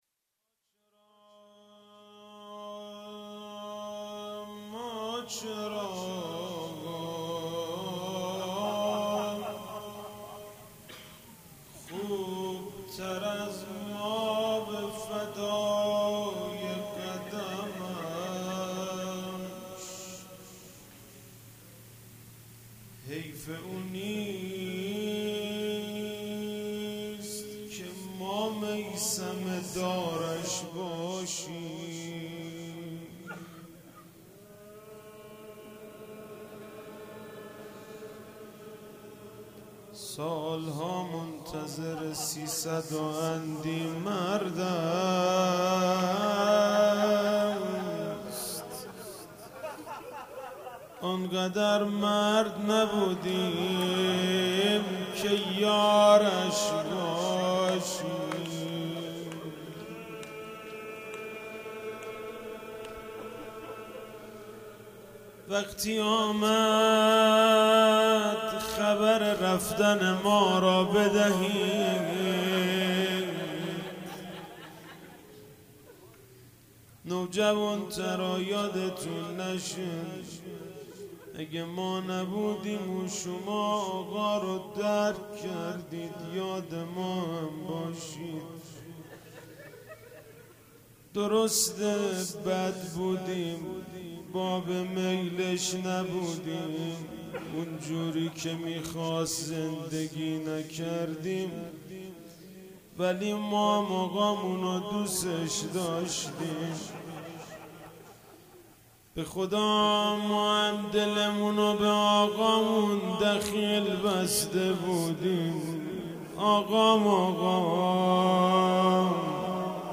شب 4 فاطمیه 95 - روضه - ما چرا خوب تر از مابه فدای قدمش